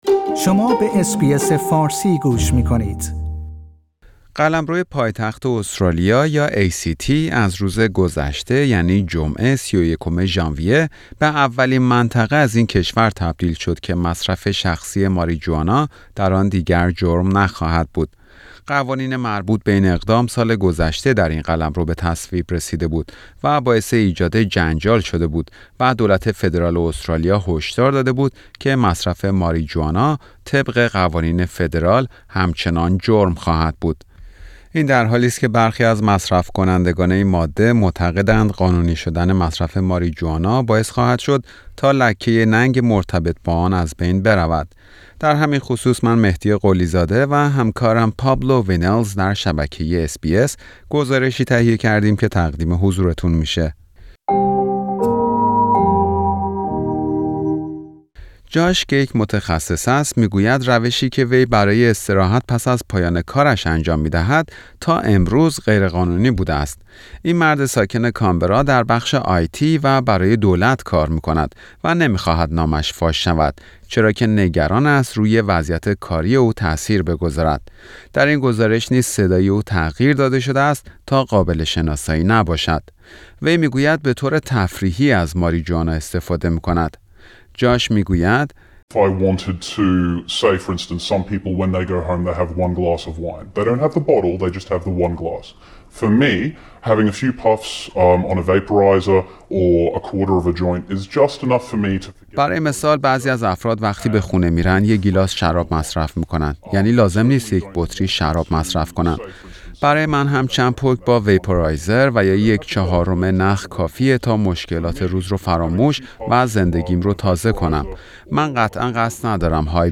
در این گزارش نیز صدای او تغییر داده شده است تا قابل شناسایی نباشد.